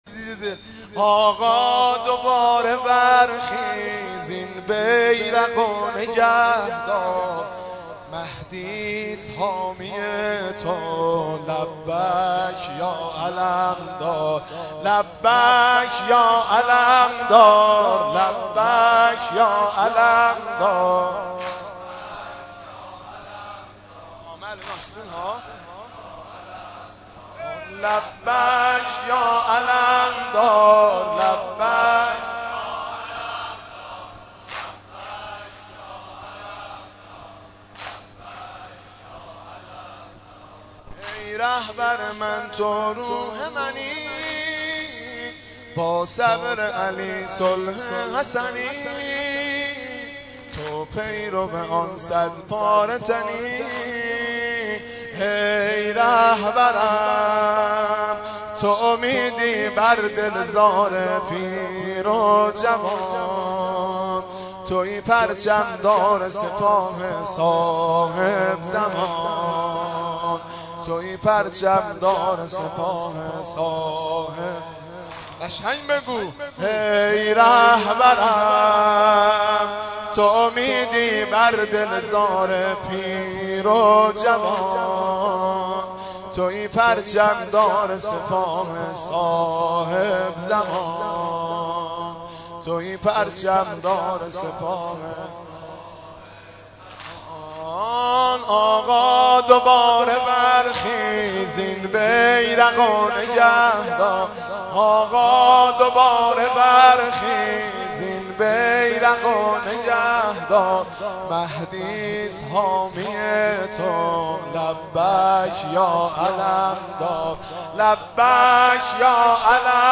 در هیئت ثارالله زنجان
مداحی
در جلسه این هفته هیئت ثارالله استان زنجان